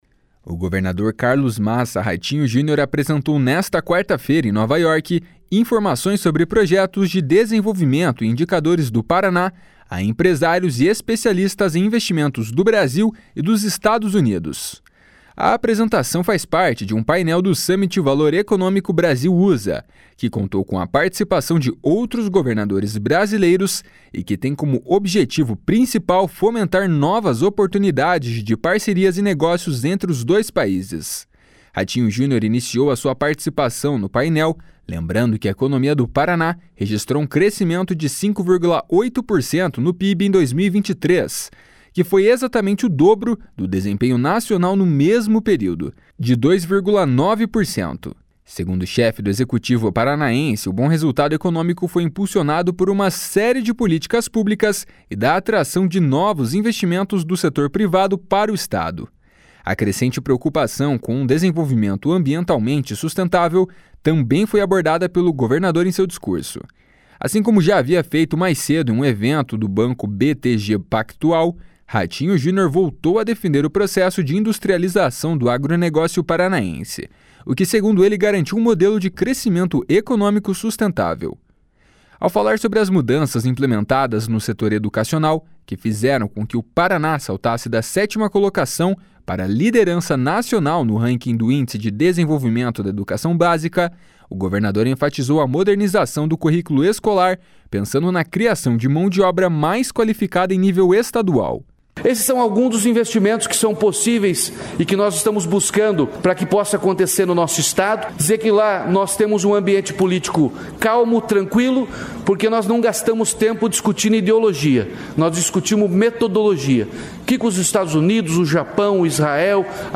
// SONORA RATINHO JUNIOR //